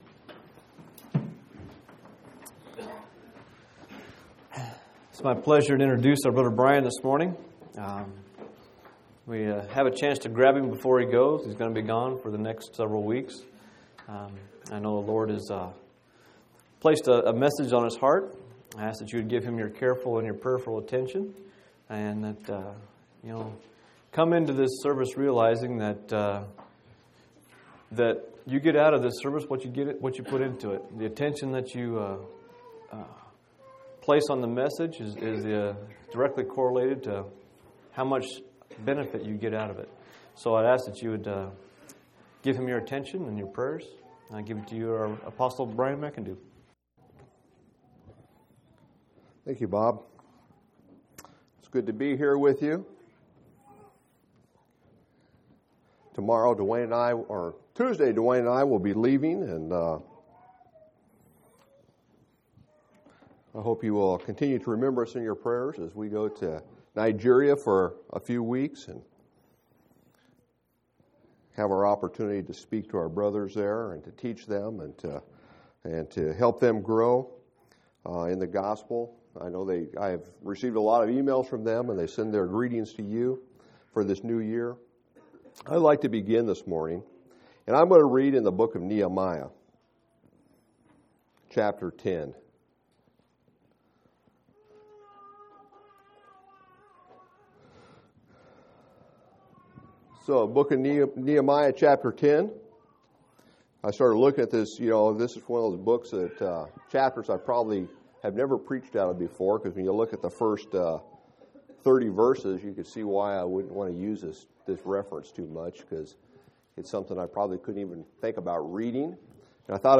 1/9/2005 Location: Phoenix Local Event